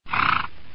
PixelPerfectionCE/assets/minecraft/sounds/mob/horse/breathe1.ogg at mc116
breathe1.ogg